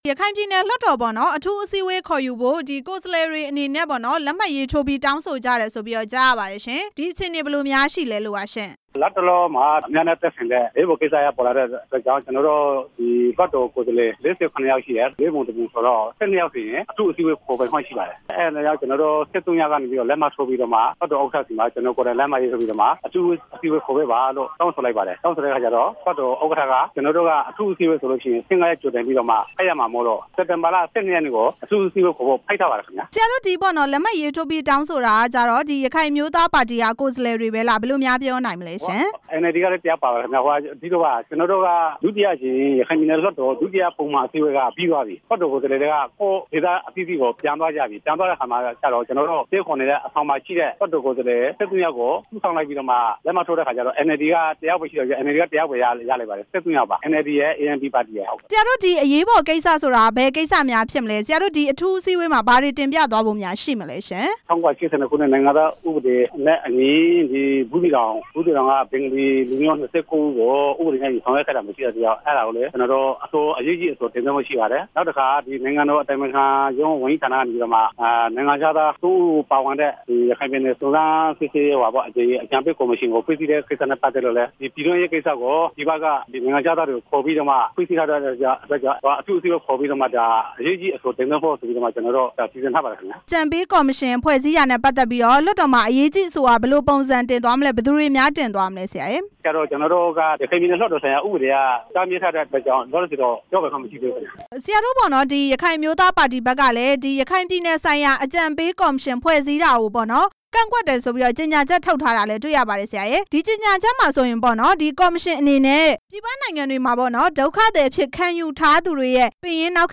ရခိုင်ပြည်နယ်ဆိုင်ရာ အကြံပေးကော်မရှင် ဖွဲ့စည်းတဲ့အပေါ် မေးမြန်းချက်